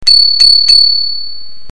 Veloglocke
veloglocke.mp3